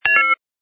sfx_ui_react_denied01.wav